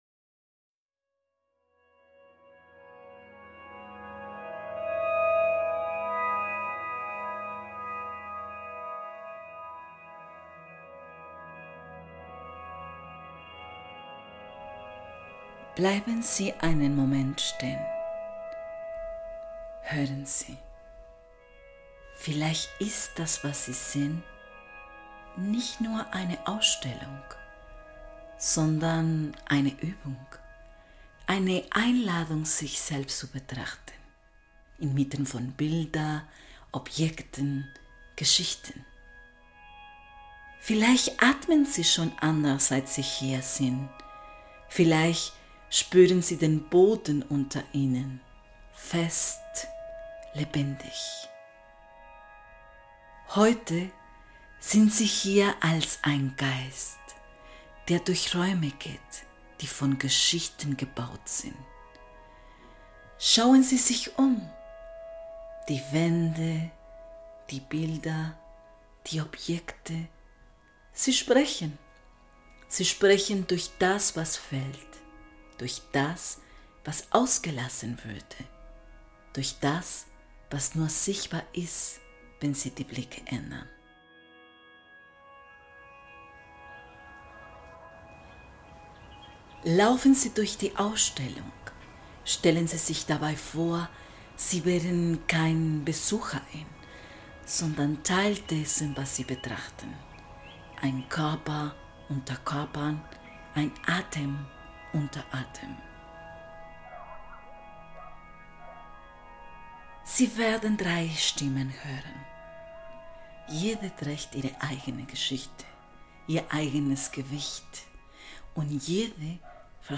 Polyphonie des Lebens - ein Audiowalk